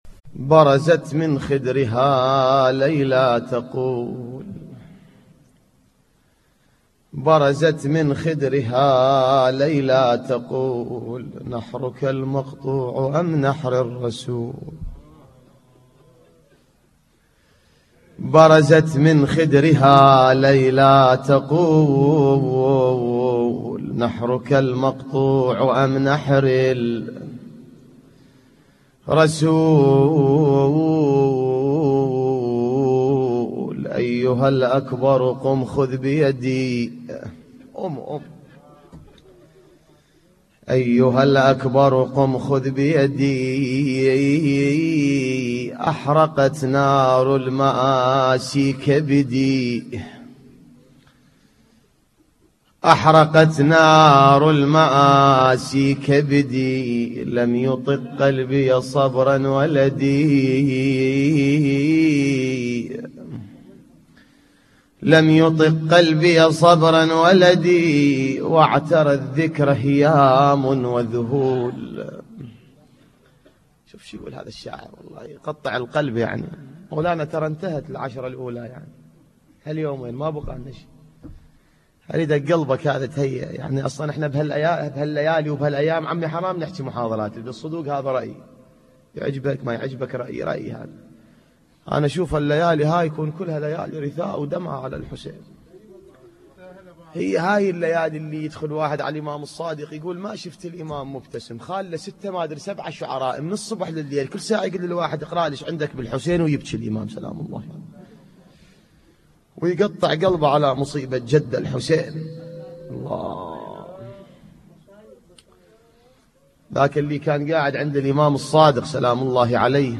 نواعي حسينية 17